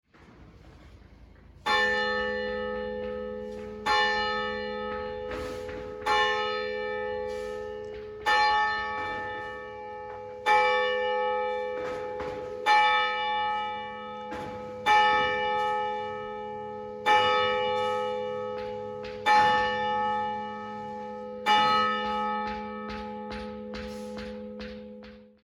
Звуки церковного колокола
Колокол отбивает десять часов